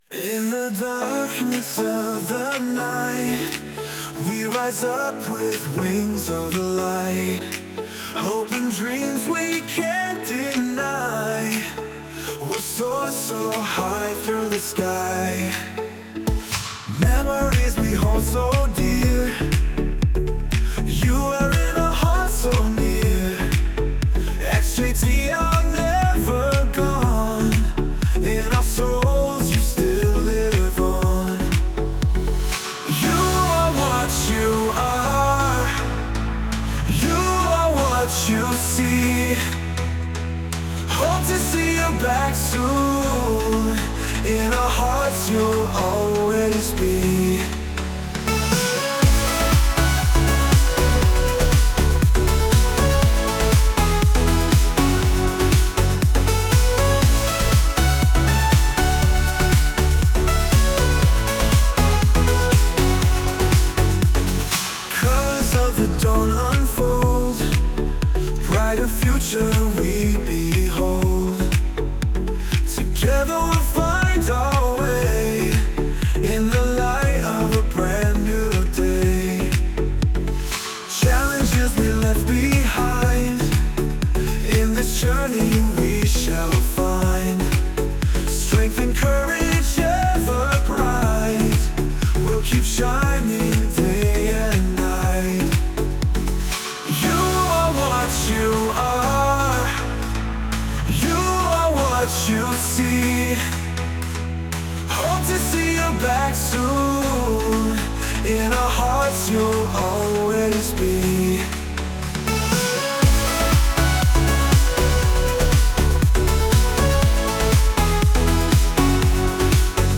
The AI-generated songs